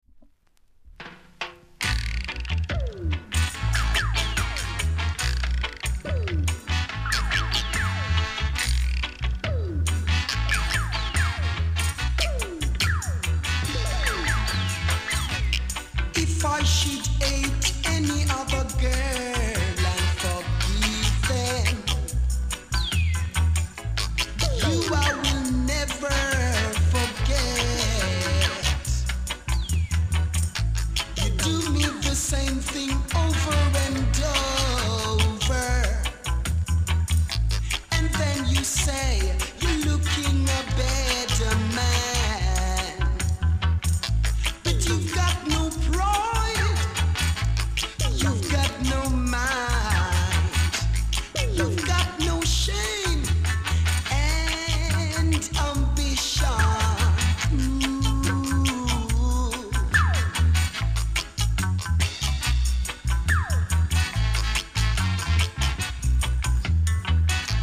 ※小さなチリノイズが少しあります。
コメント DEEP VOCAL!!RARE!!※オリジナルのカンパニースリーブ付き（G+）